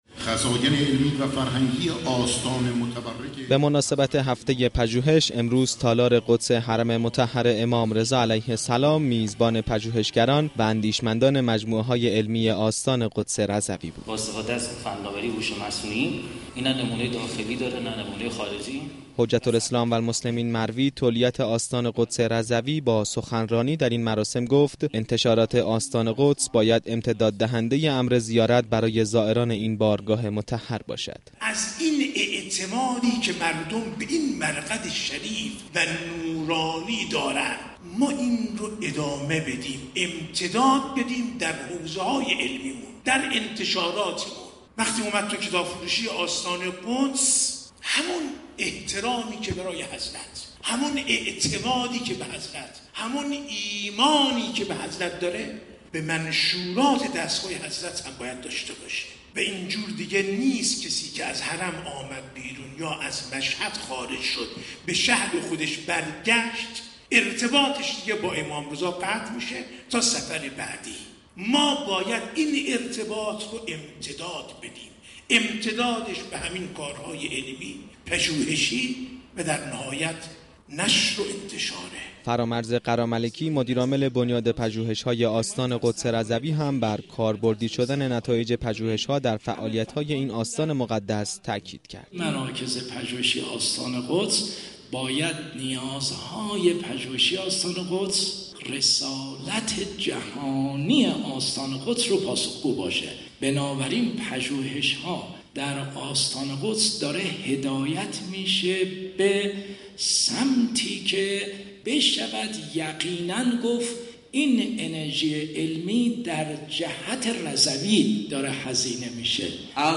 گردهمایی هفته پژوهش و فناوری و بزرگداشت روز وحدت حوزه و دانشگاه در محل تالار قدس حرم مطهر رضوی
تولیت آستان قدس رضوی در جمع پژوهشگران گفت:گسترش و نوسازی مراكز علمی و فرهنگی حرم مطهر رضوی به واسطه زحمات آیت الله طبسی میسر شد و این نشانه ای از پیشرفت و رشد است